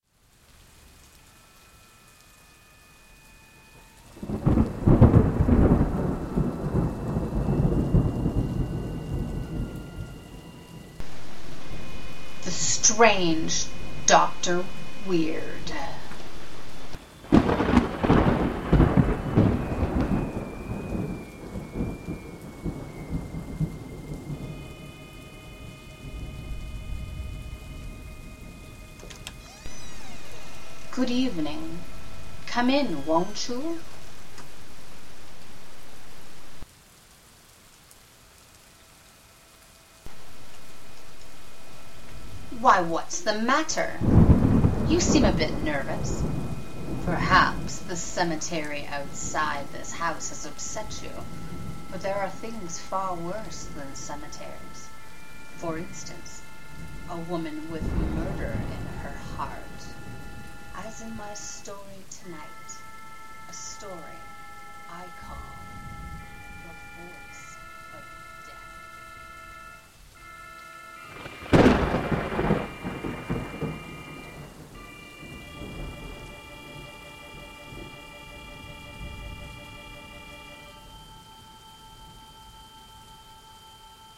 Other than my home computer, Audacity and other internet-based resources, I’ve got a $95 headset complete with a microphone and earphones.
For my radio show I need: thunder, door, cross-country skis on snow, avalanche, wind,  ice cracking, splashing, clock striking 5 o’clock, etc.  There are 4 characters plus the announcer.
So far, here is a four track recording of the intro.
Very dark, dramatic, and the style of your mic makes it sound like you’re in a dark room telling the story (or did you process that?).